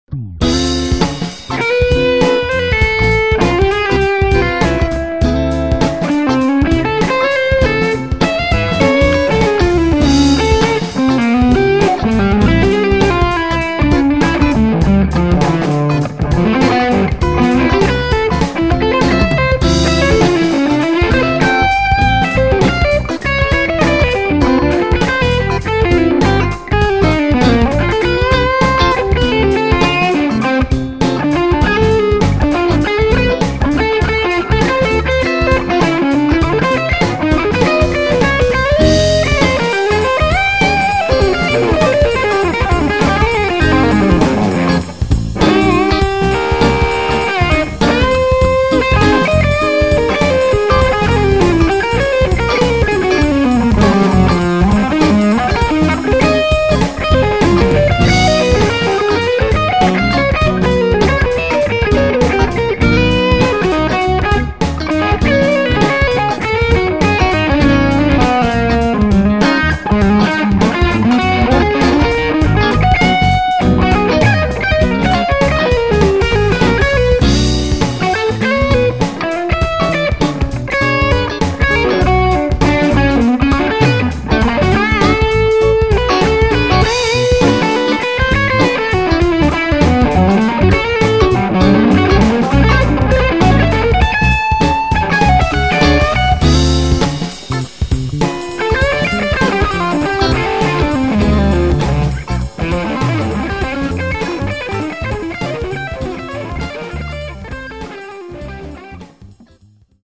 Here is another amp. Same exact setup, I just swapped the heads.
Clip two - more high end content off the git go, but thinner lows and something in the mids that doesn't get it for me.
The 100 watter in the second clip is not finished.
Still nice and chewy but nice smooth top end that makes it cut through better without being harsh.